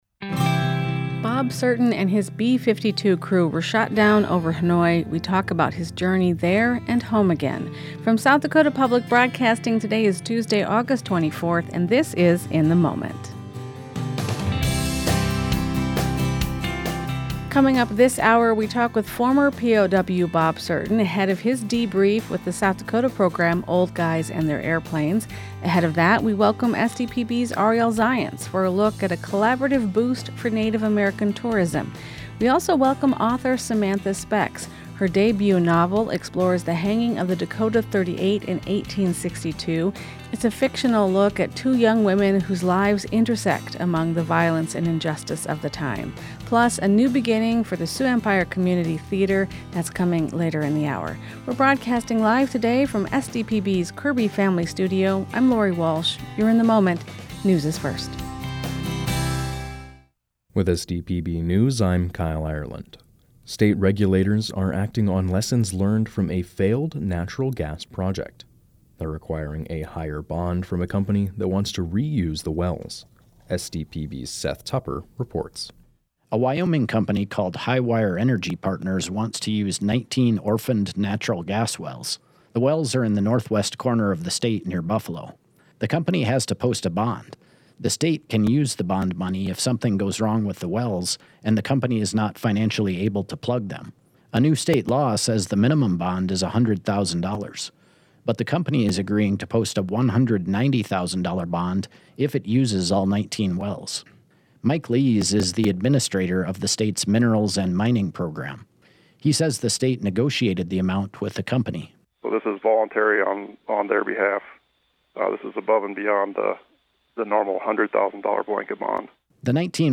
In the Moment is SDPB’s daily news and culture magazine program.